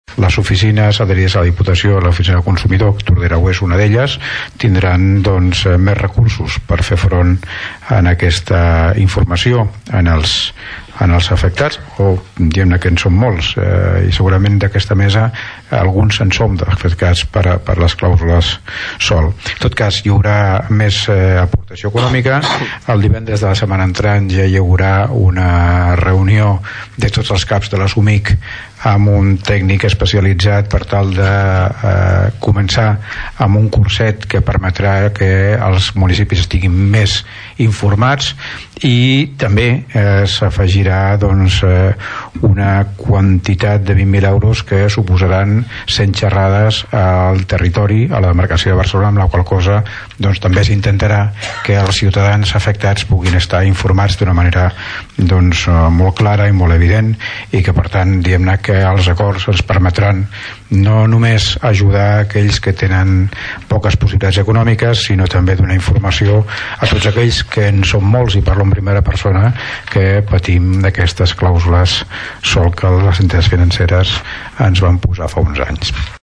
L’alcalde de Tordera, Joan Carles Garcia explica que la diputació de Barcelona ha aprovat destinar més recursos econòmics a les oficines dels consumidors per assessorar i informar als ciutadans afectats.
clausules-alcalde.mp3